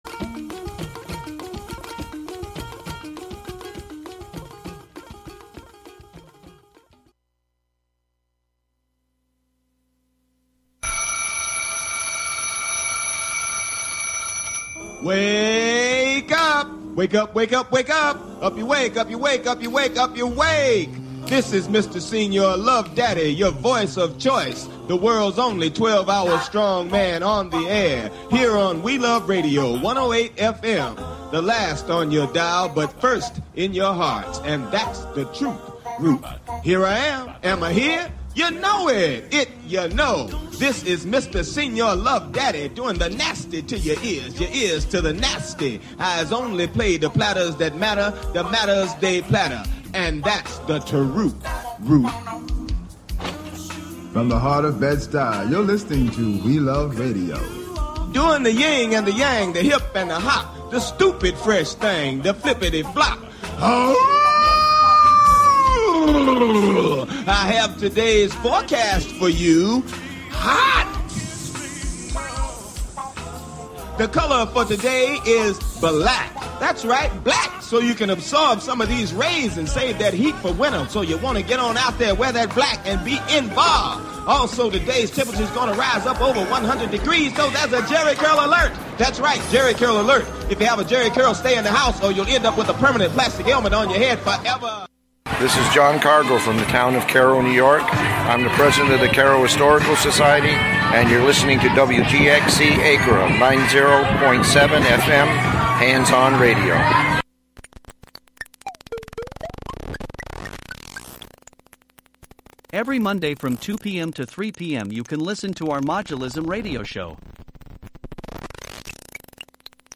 Introspective music